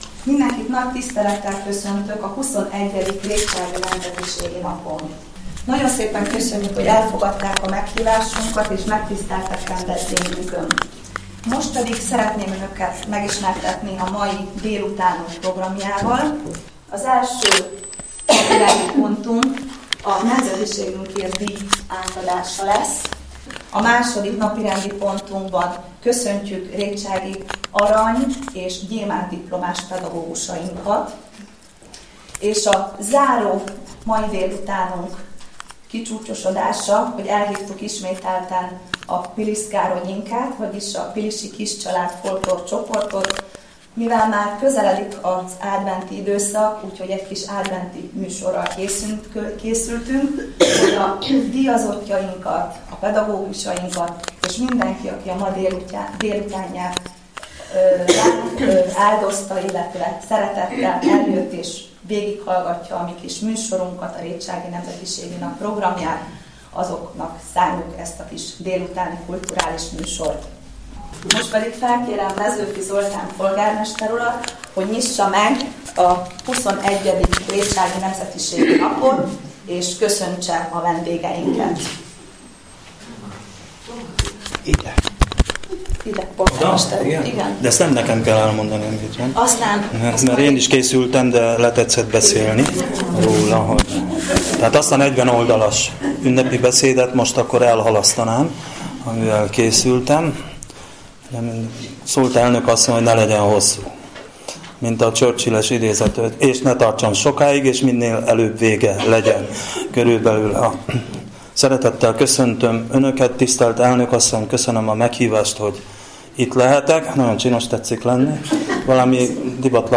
A terem ugyancsak megtelt délután 3 órára. A Szlovák nemzetiség Himnuszának közös eléneklése után Salgai Szilvia, a nemzetiségi önkormányzat elnöke köszöntötte a megjelenteket szlovák és magyar nyelven. Ismertette a tervezett programpontokat, majd felkérte Mezõfi Zoltánt, Rétság város polgármesterét, hogy köszöntse a megjelenteket.